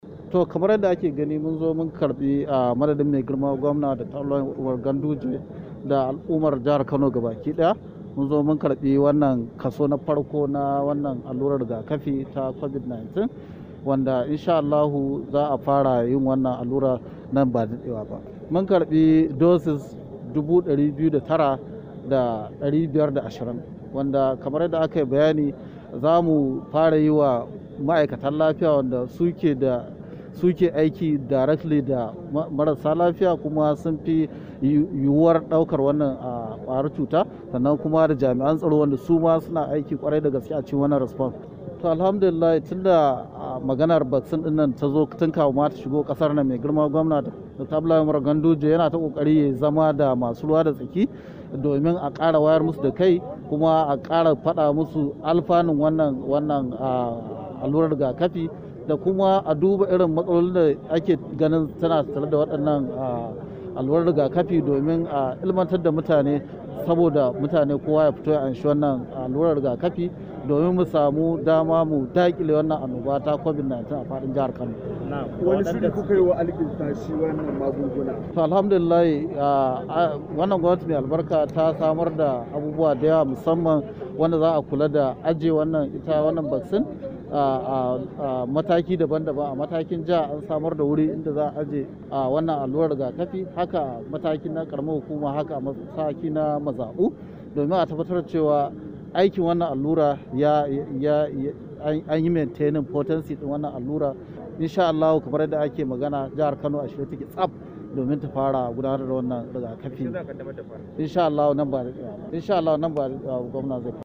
Latsa abin da ke kasa, domin sauraron muryar kwamishinan lafiya na Jihar Kano, Dakta Aminu Ibrahim Tsanyawa.